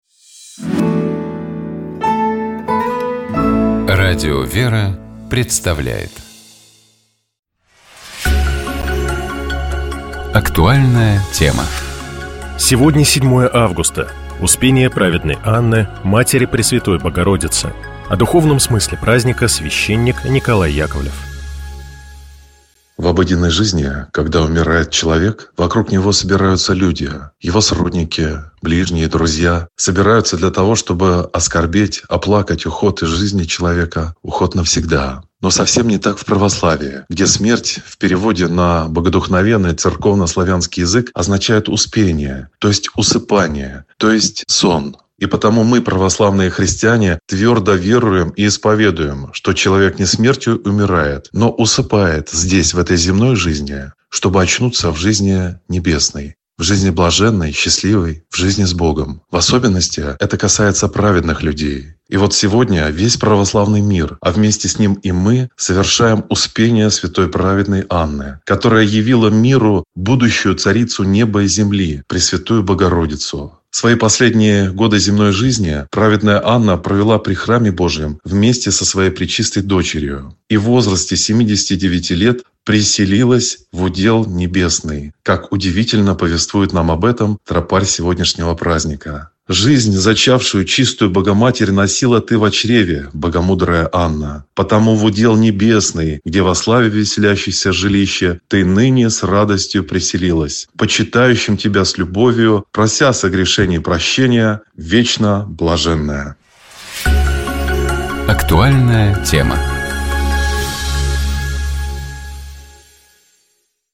О духовном смысле праздника — священник